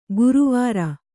♪ guru vāra